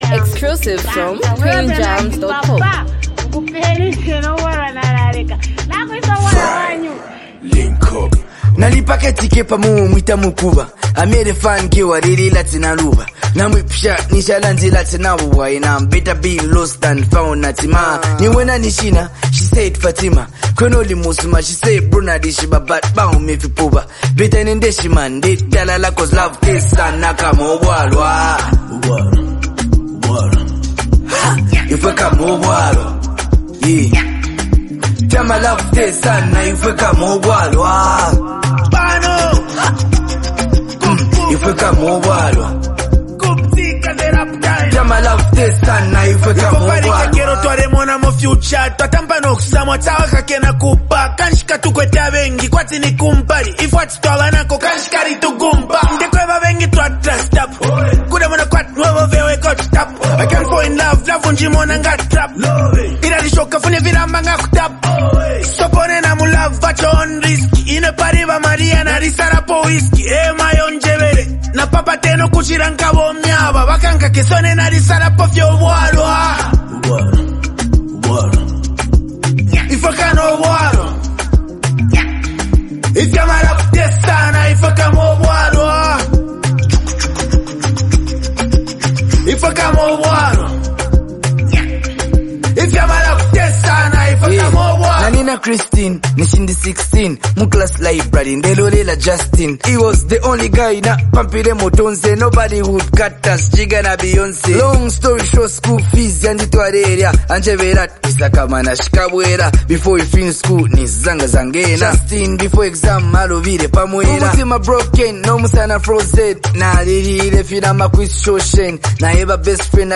heartfelt love song